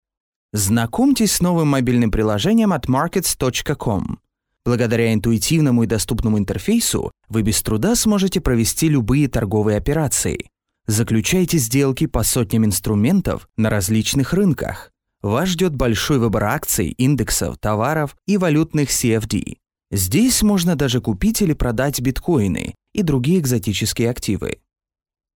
Russian and Ukrainian VO services (native in both) Professional studio, quick turnaround
Sprechprobe: Werbung (Muttersprache):
Young Adult Male Distinct articulation, educational, confident, believable and expressive style